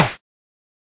eleSnare04.wav